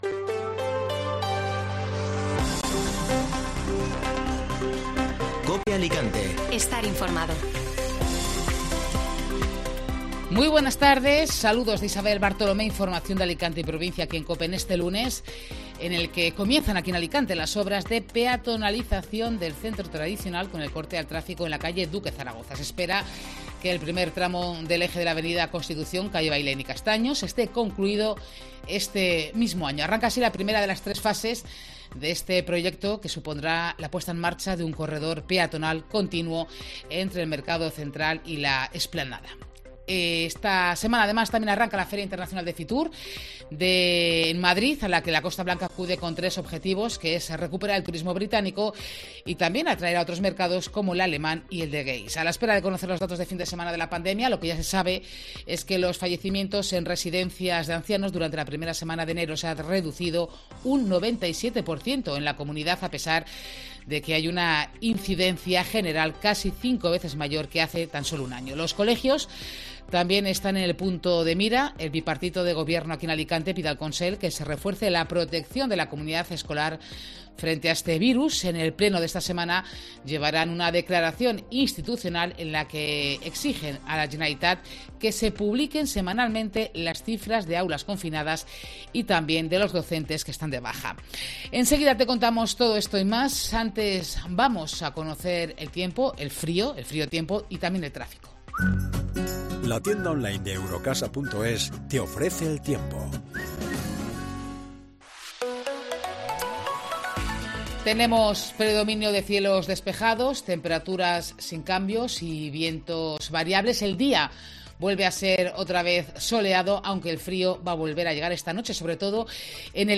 Informativo Mediodía COPE Alicante (Lunes 17 de enero)